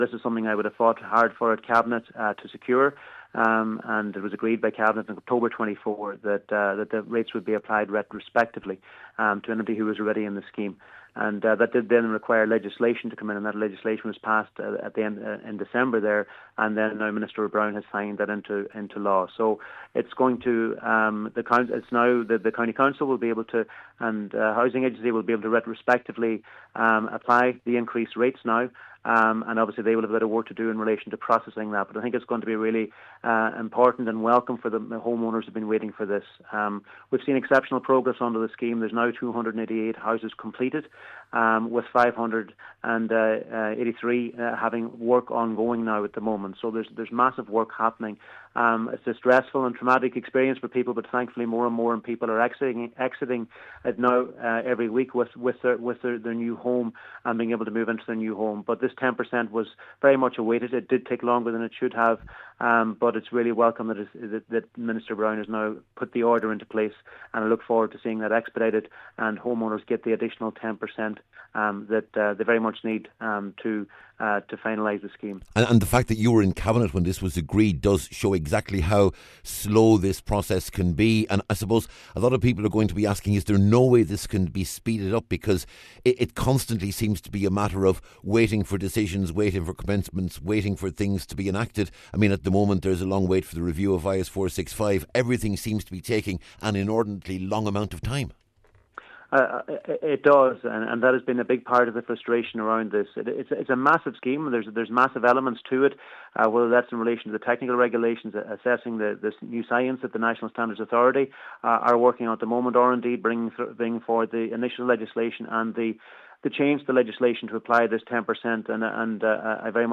Minister McConalogue says this is another important step in the evolution of the scheme, but acknowledges progress needs to be quicker…………